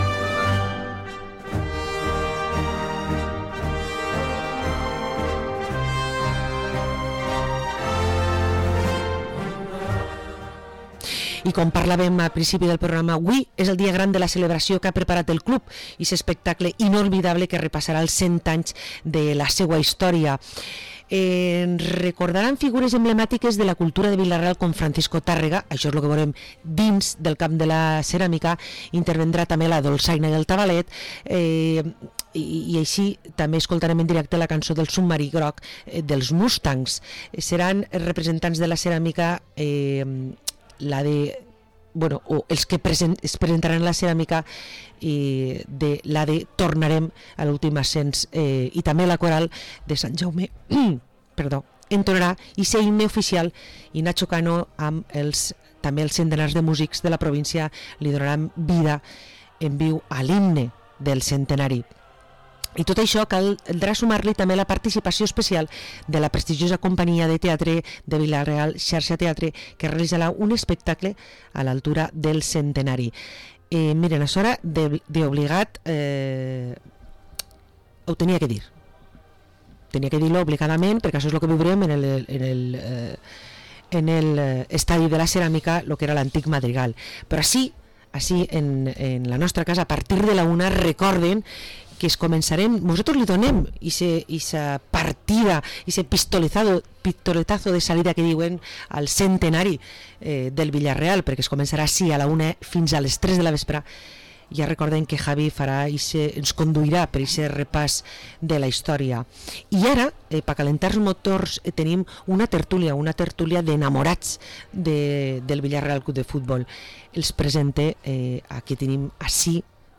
Tertúlia grogeta